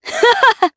Nicky-Vox-Laugh_jp.wav